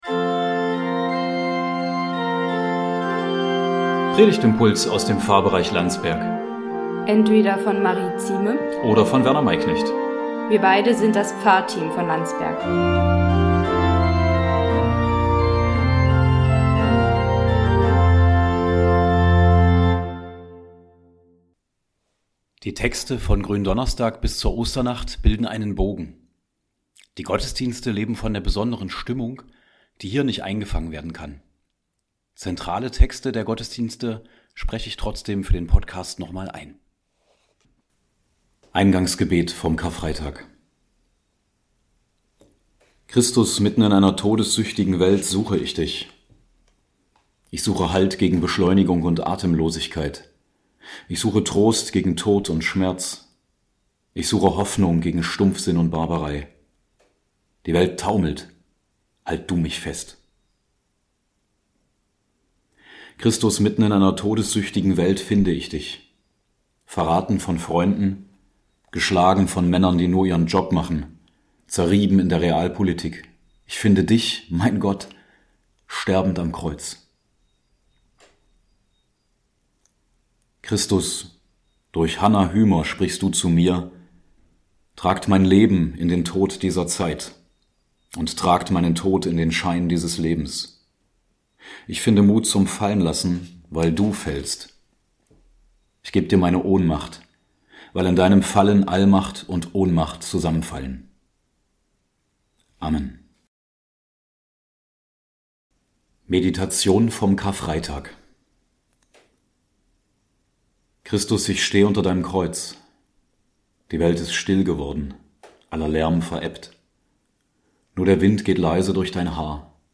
Predigtimpulse aus dem Pfarrbereich Landsberg